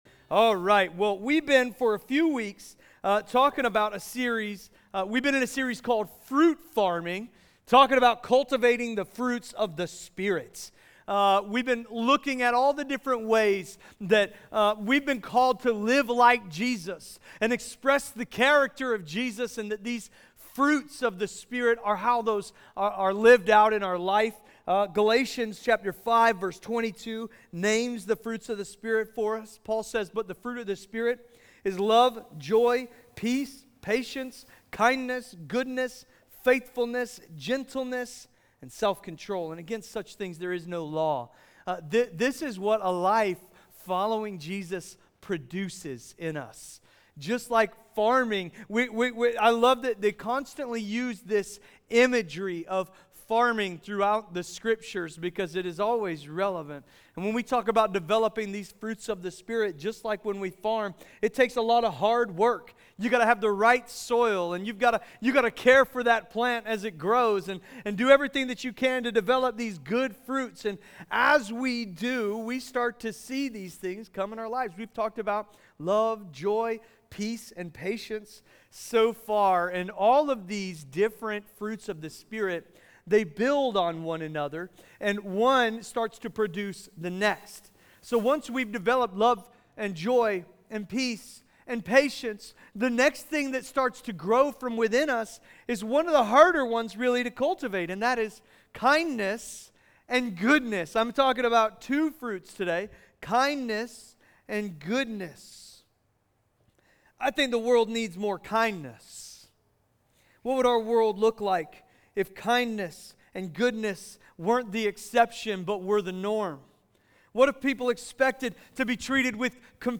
Fruit Farming KINDNESS Message.mp3